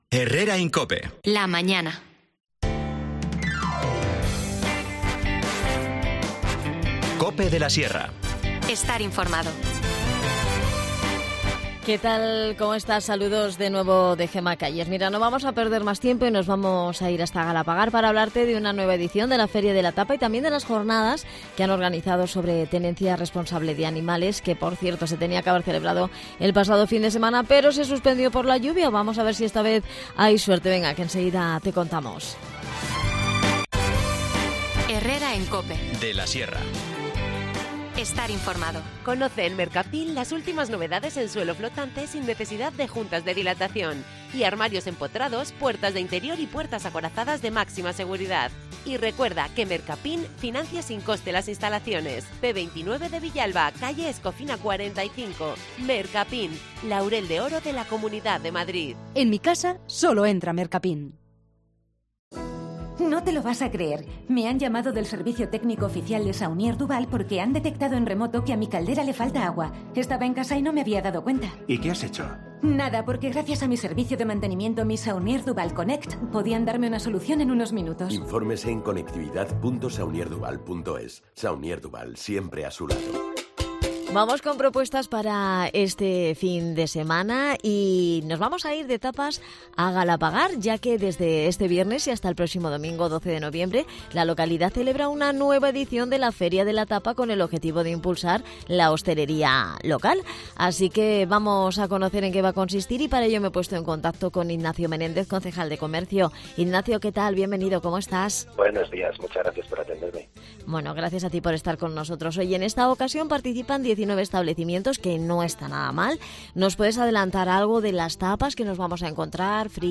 Nos cuenta todos los detalles Ignacio Menédez, concejal de Comercio.
También hablamos en el programa con Carmen González, concejal de Salud Pública, sobre las Jornadas de Tenencia Responsable de Animales Domésticos que se van a celebrar los días 4 y 5 de noviembre en la Plaza de la Constitución de 11:00 a 15:00 horas.